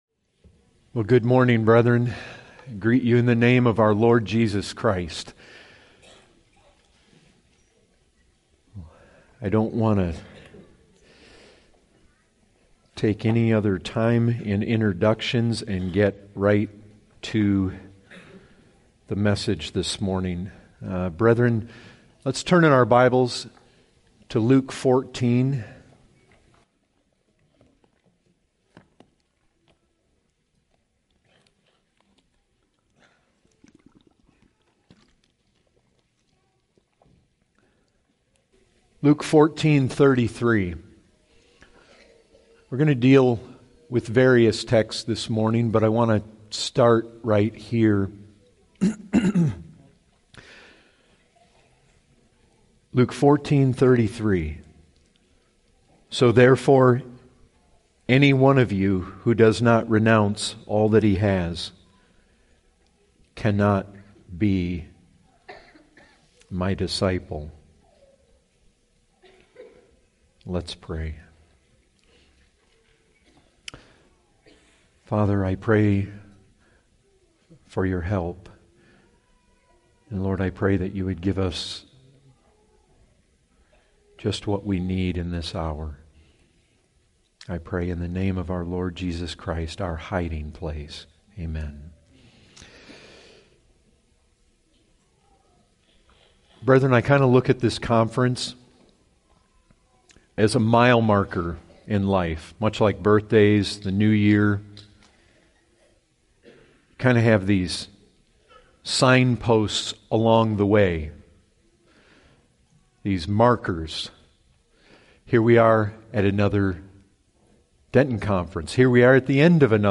2017 Fellowship Conference